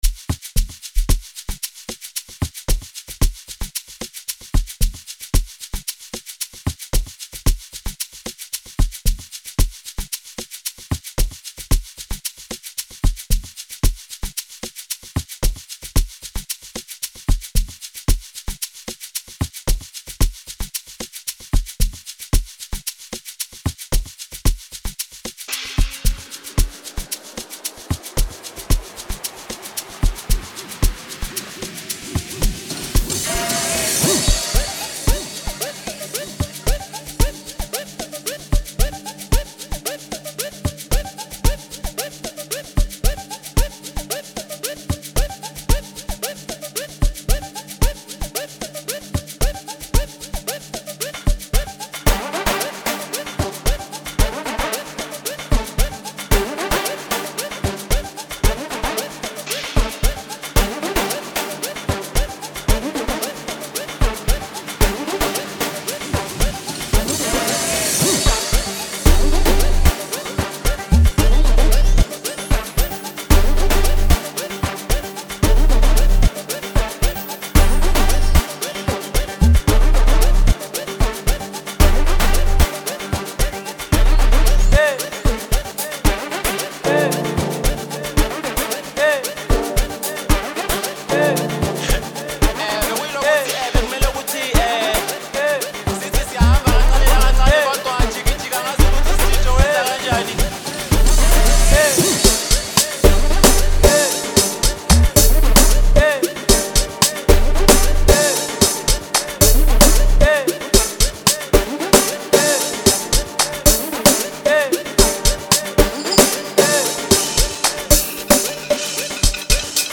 a South African producer and amapiano artist